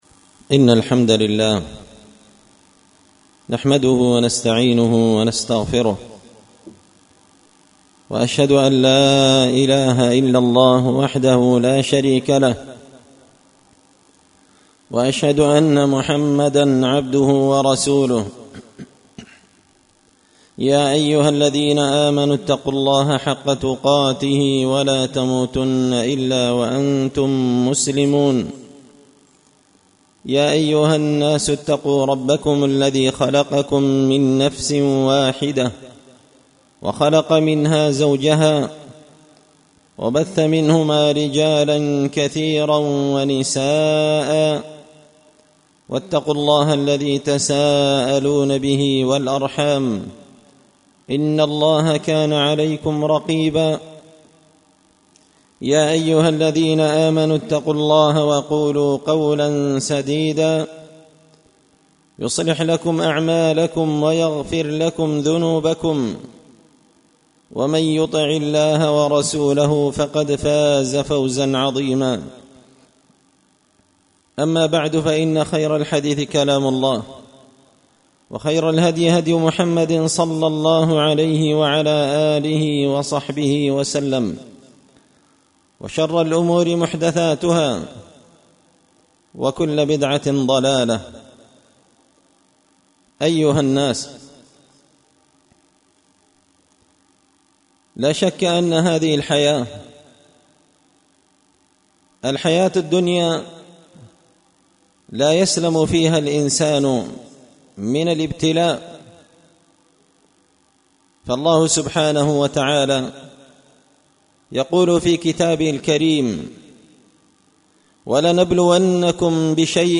خطبة جمعة بعنوان – أسباب الأمراض وطرق السلامة منها
دار الحديث بمسجد الفرقان ـ قشن ـ المهرة ـ اليمن